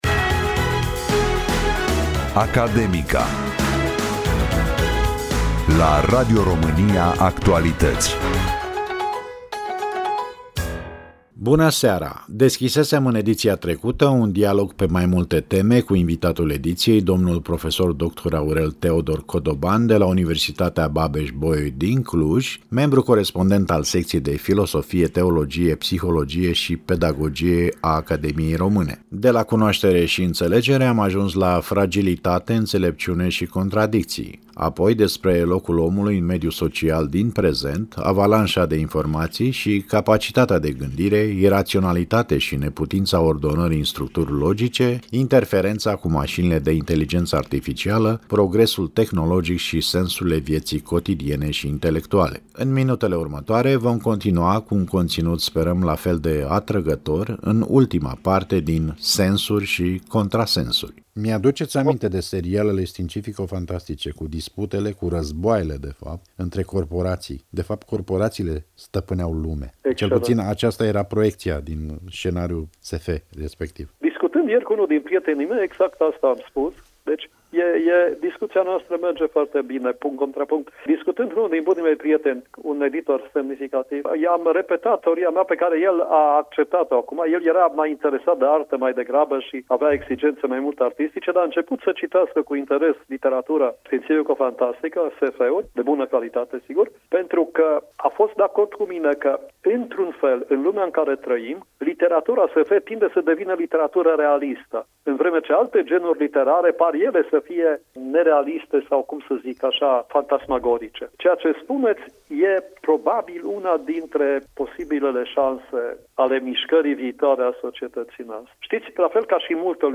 17 decembrie 2025 Interviu Radio România Actualități – Emisiunea „Academica“ Partea a II-a - 14 ianuarie 2026 Invitat al emisiunii „Transparențe”